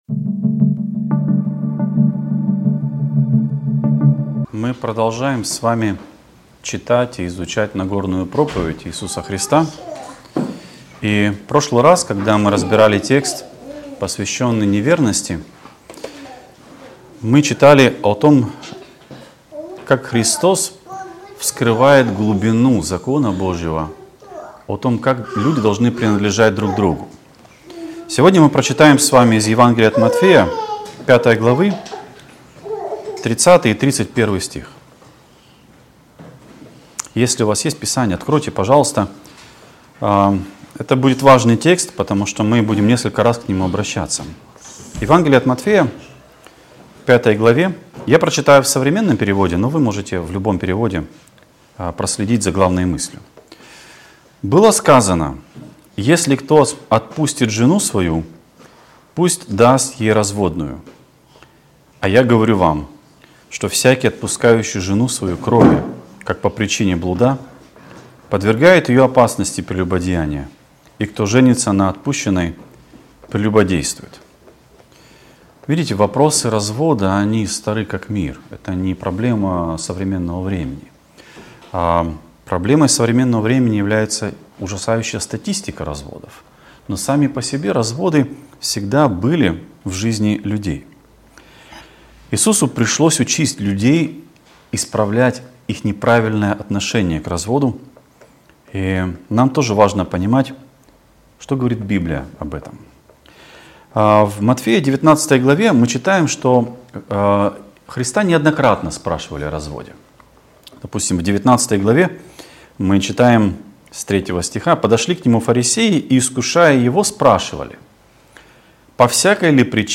Проповедник